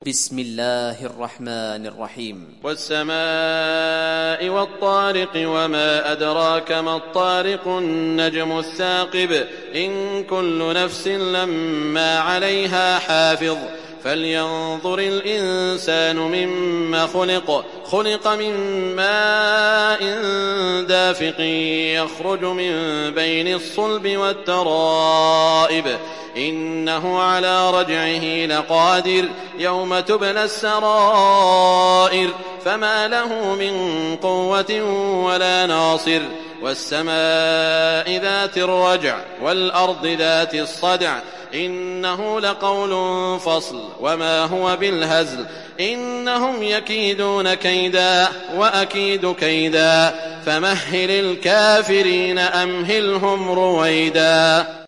Surat At Tariq Download mp3 Saud Al Shuraim Riwayat Hafs dari Asim, Download Quran dan mendengarkan mp3 tautan langsung penuh